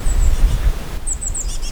Gesang einer Meise
Die breitbandigen Töne (Chirp) täuschen einen großvolumigen Resonanzkörper vor. Die Töne erscheinen daher sehr viel tiefer  (s.a. Krähe).
Der Vogel belegt breite Freqenzbereiche und erzeugt dabei den Eindruck des oberen Endes einer langen Obertonreihe d.h. es handelt sich um einen tiefen Ton, von dem nur ein Bruchstück, nämlich die hohen Obertöne zu hören ist.   0 bis 2 Sekunden: